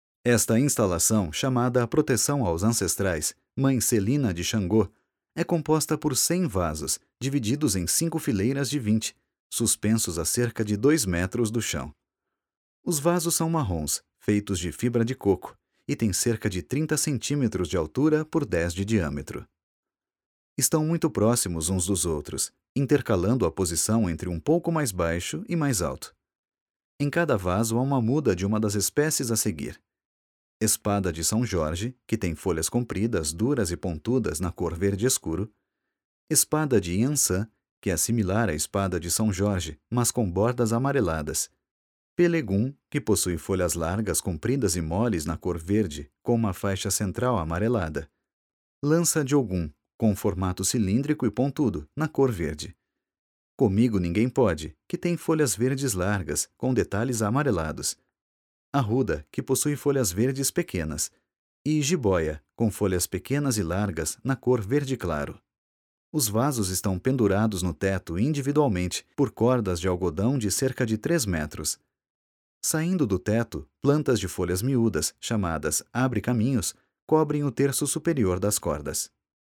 Audiodescrição